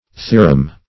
Theorem \The"o*rem\, v. t.